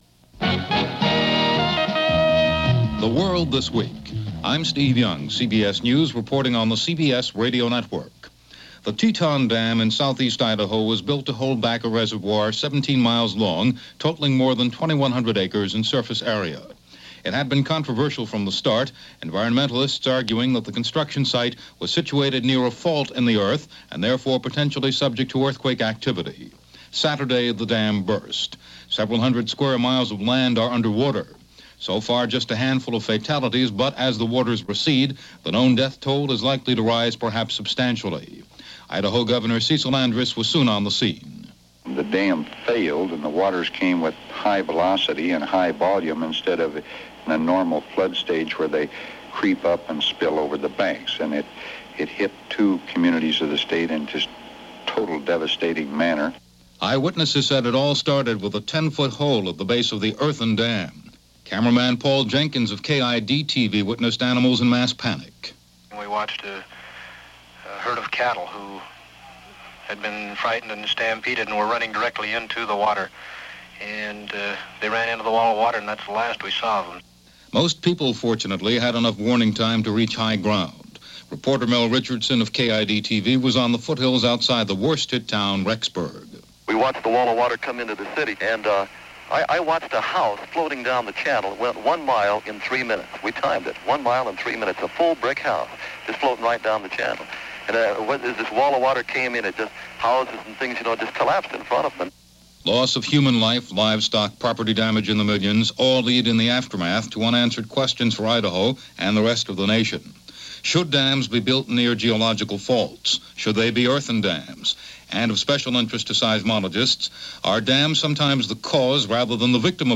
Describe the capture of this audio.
And that’s just a sample of what happened this week, which ended on June 6, 1976 as reported on CBS Radio’s World This Week.